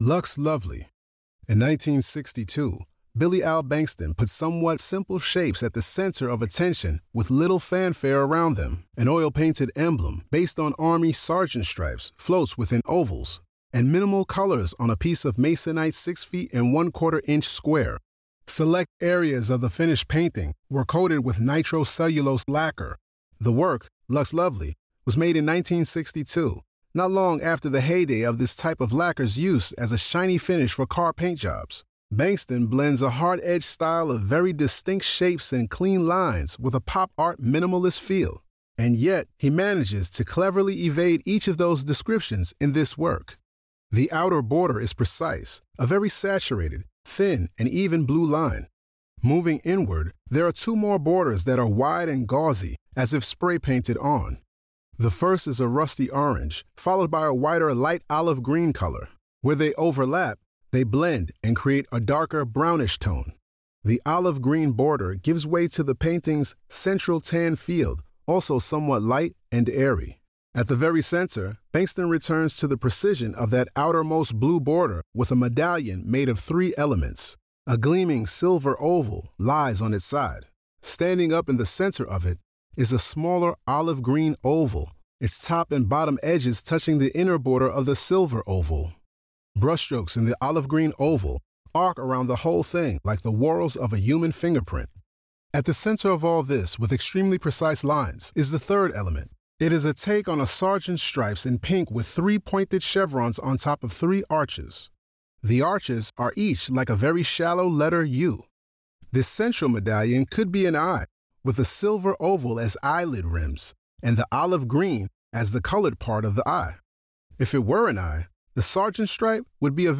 Audio Description (02:41)